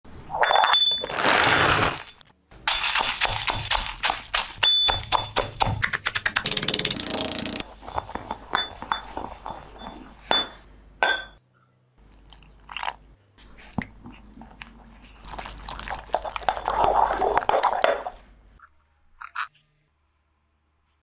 Upload By ASMR videos
Guess final results of crushing